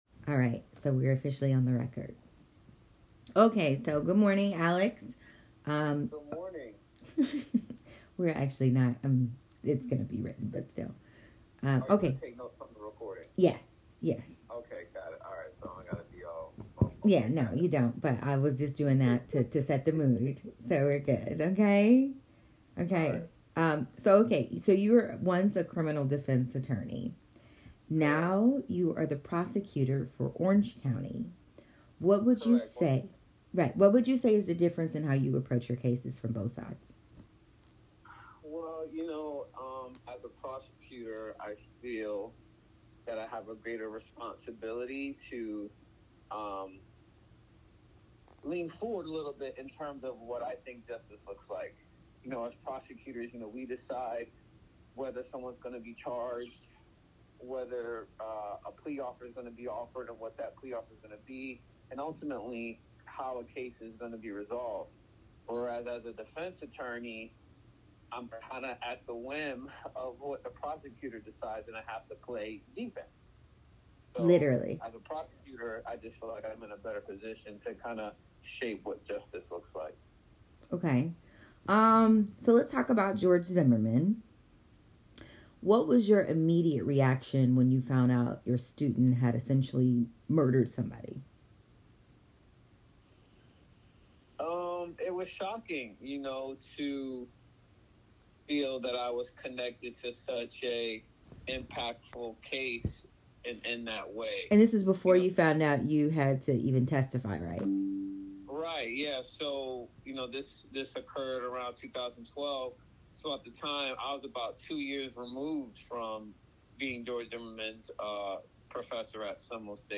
The interview was originally going to be written, but with his permission, we decided to present the audio version.